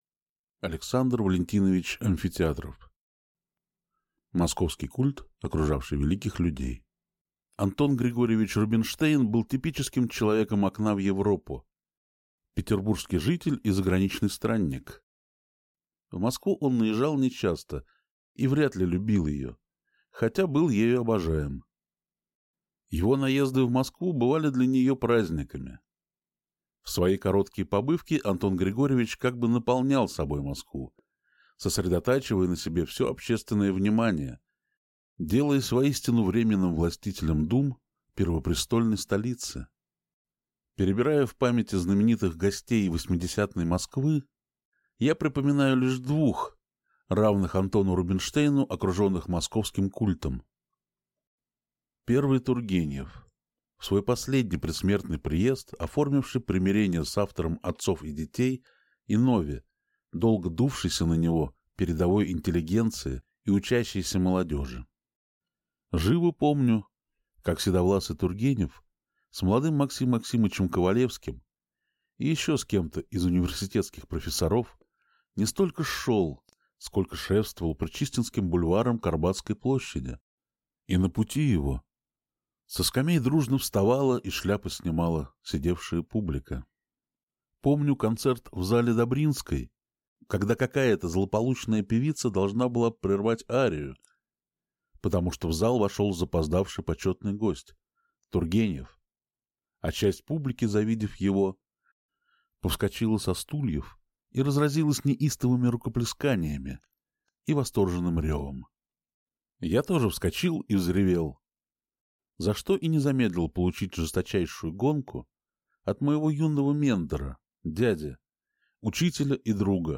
Аудиокнига Московский культ, окружавший великих людей | Библиотека аудиокниг